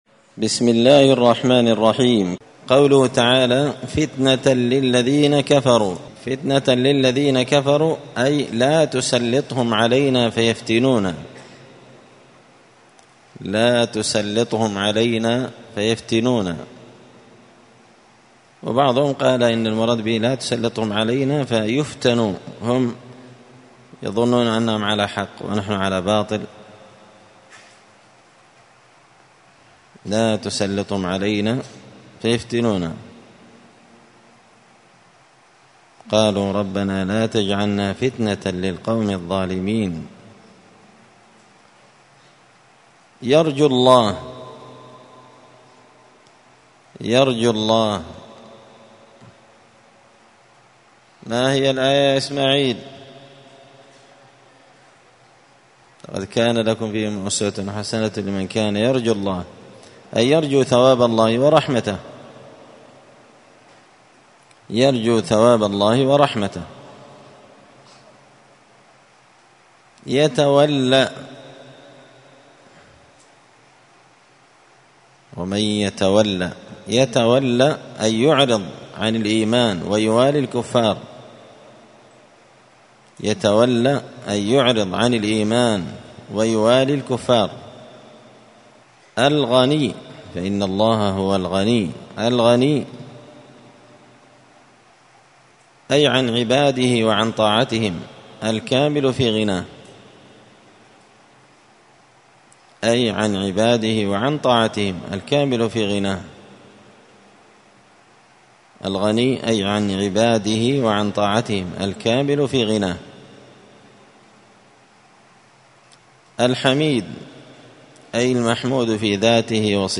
زبدة الأقوال في غريب كلام المتعال الدرس الخامس والثلاثون بعد المائة (135)